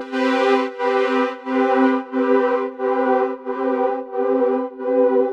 Index of /musicradar/sidechained-samples/90bpm
GnS_Pad-MiscB1:4_90-C.wav